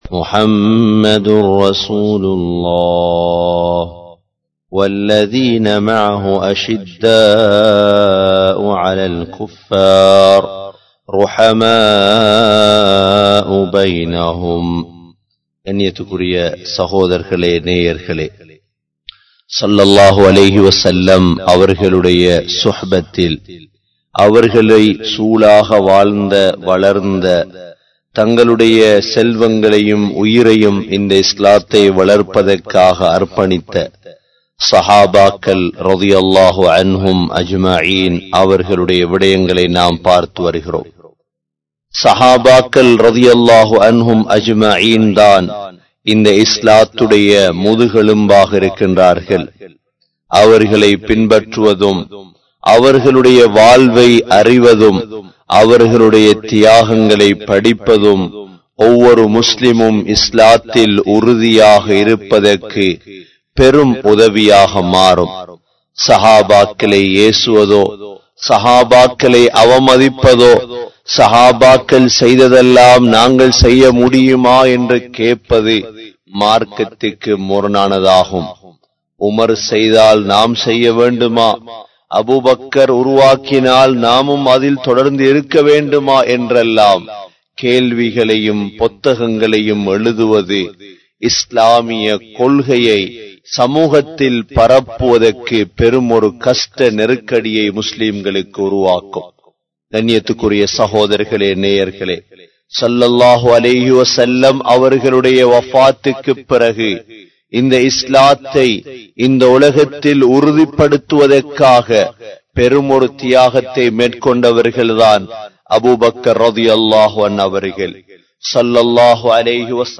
Hazarath Abu Bakr(Rali)Avarhalin Mun Maathirihal (ஹஸரத் அபுபக்கர்(ரலி)அவர்களின் முன்மாதிரிகள்) | Audio Bayans | All Ceylon Muslim Youth Community | Addalaichenai